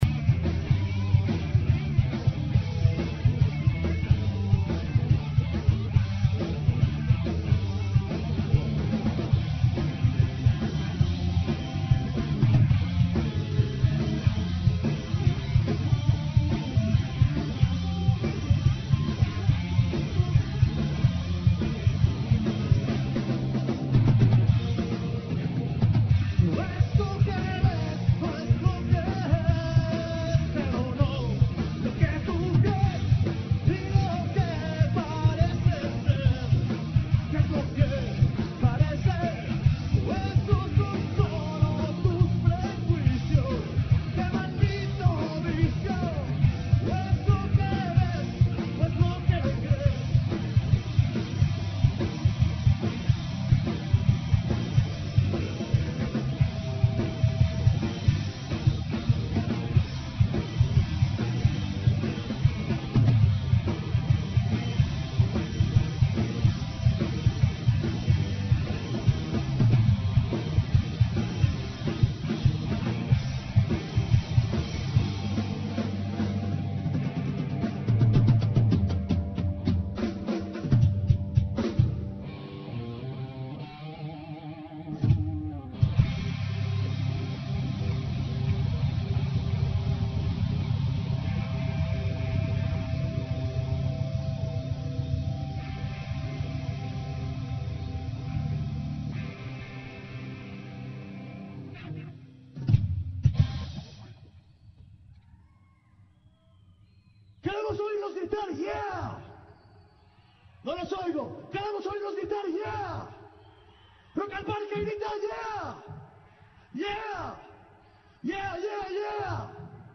The interview features Colombian singer-songwriter Lucrecia Dalt, based in Berlin, who performed at the Eco stage of Rock in the Park Festival 2017. Dalt discusses her international career, collaborations with German record labels, and the preparation of a new album. She also reflects on the importance of creating spaces for women in rock and recognizing their creativity and talent.